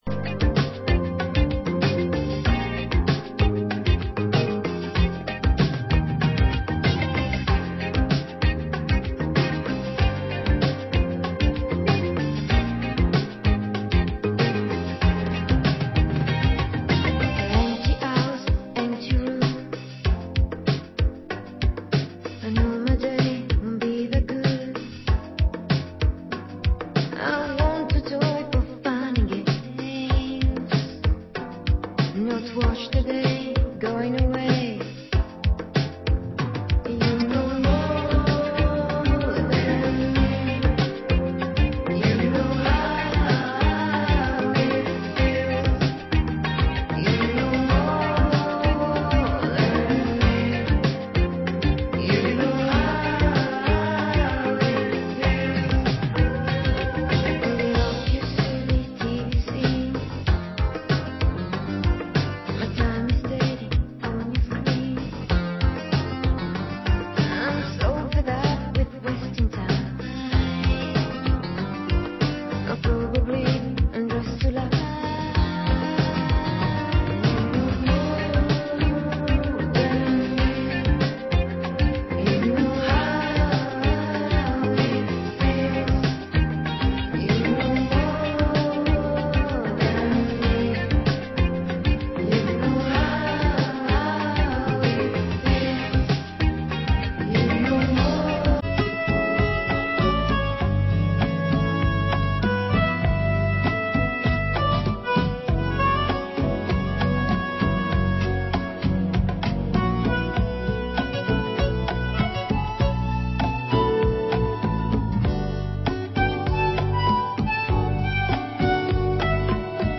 Genre: Balearic